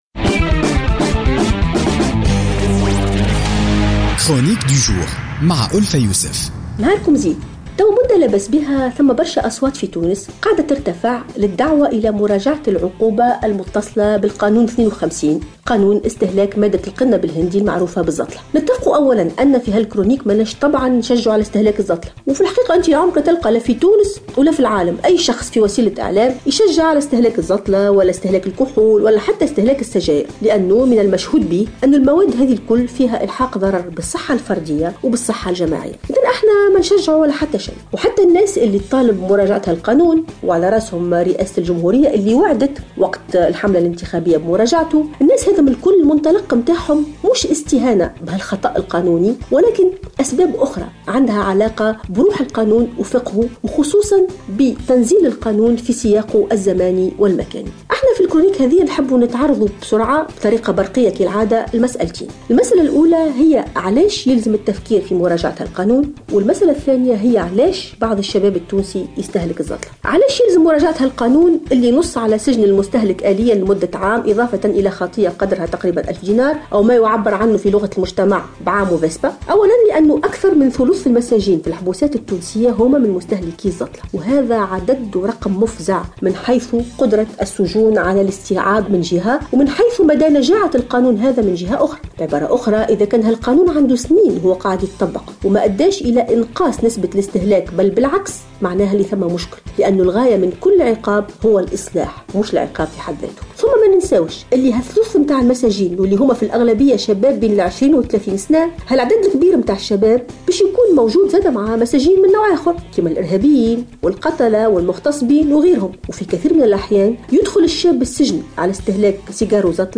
تطرقت الكاتبة ألفة يوسف اليوم الخميس في افتتاحيتها بـ "الجوهرة أف ام" اليوم الخميس، إلى مسألة القانون المتعلق بمستهلكي مخدر القنب الهندي المعروف في تونس في تونس بـ "الزطلة".